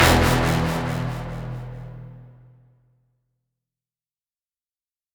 Metro Narcatics Hit.wav